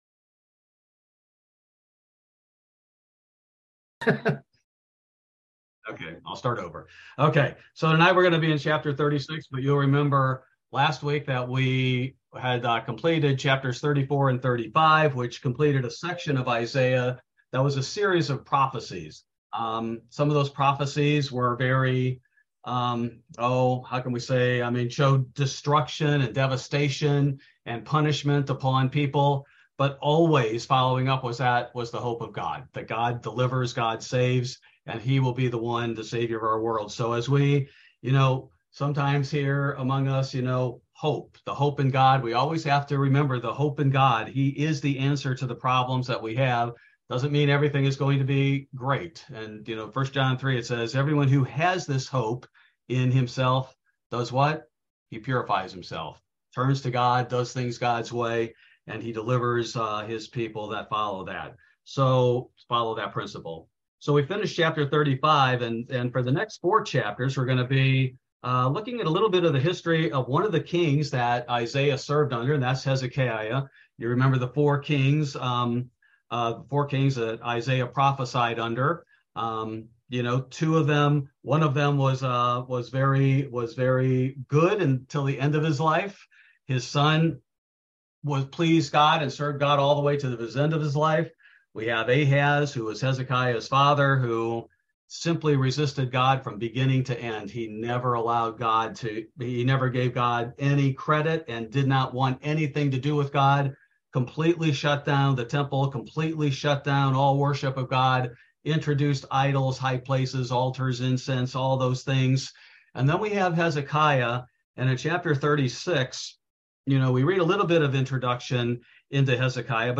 Bible Study: June 31, 2023